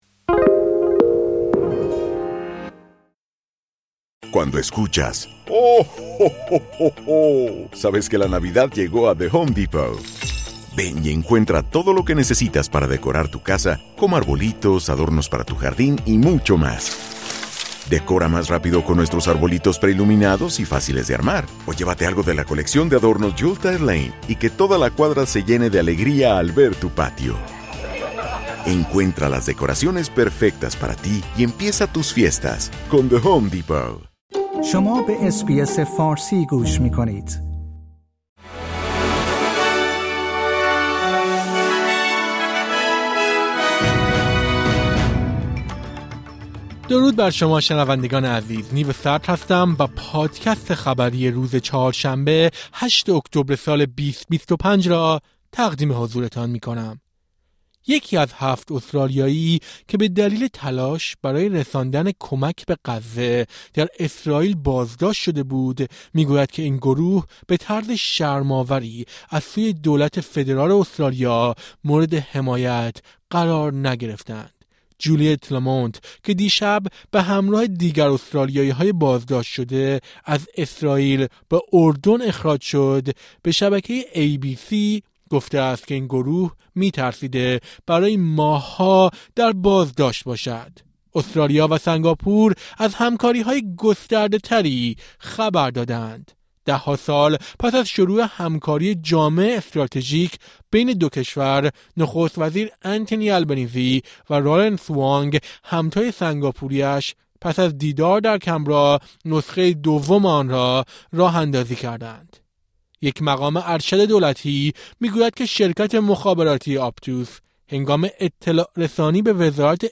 در این پادکست خبری مهمترین اخبار روز سه‌شنبه هشتم اکتبر ارائه شده است.